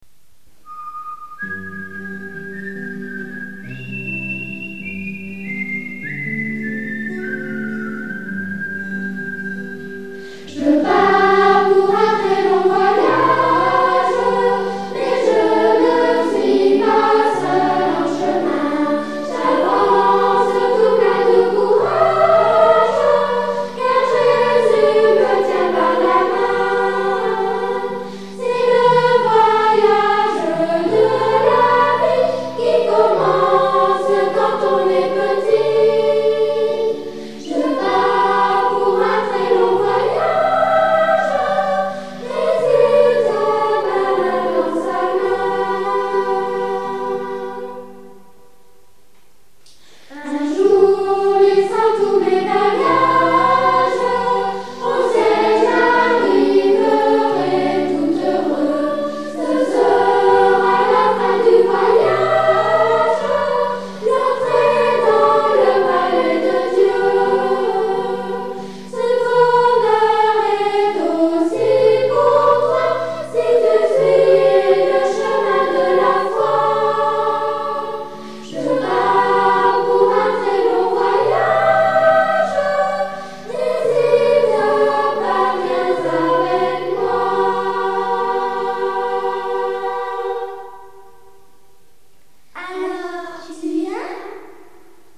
Un nouveau chant : Je pars pour un très long voyage !